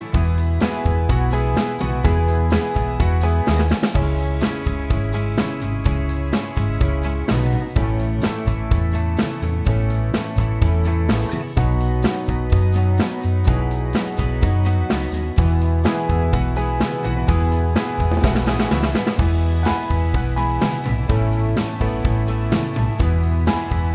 Minus All Guitars Easy Listening 2:26 Buy £1.50